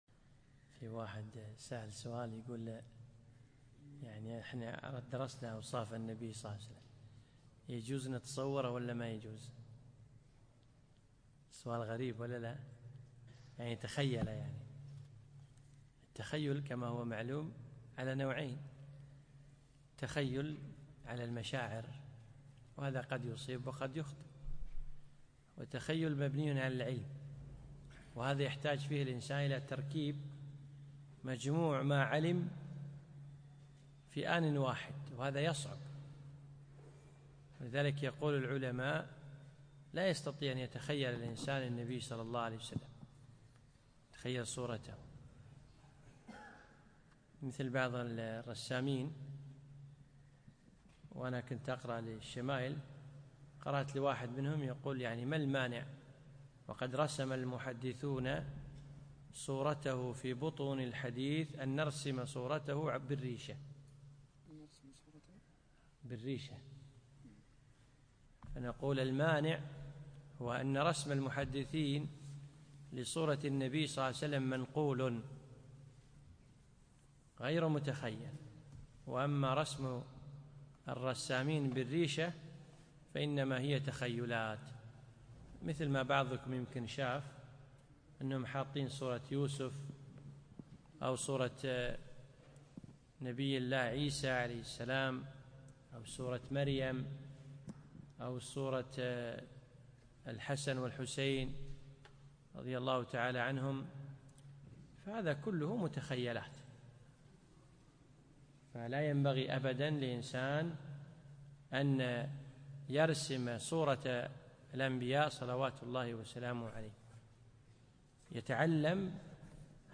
يوم الأثنين 22 ربيع الأخر 1437هـ الموافق 1 2 2016م في مسجد عائشة المحري المسايل